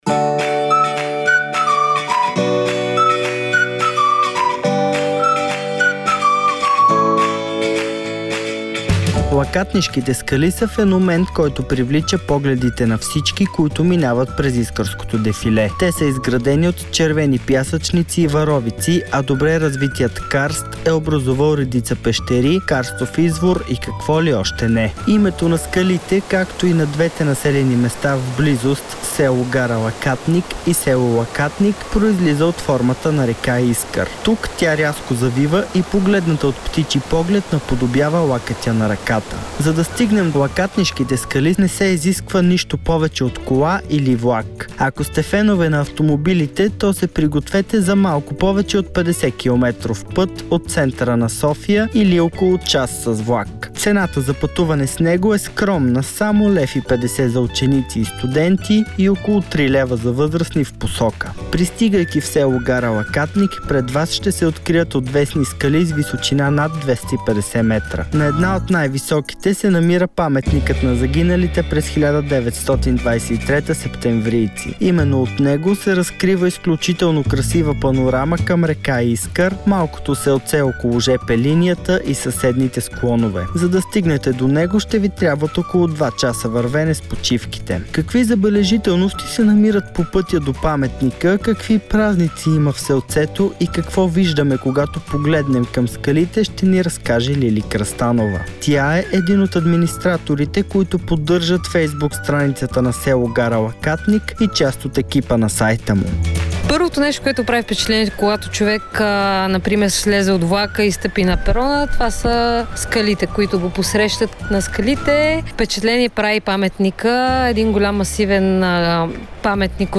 репортажа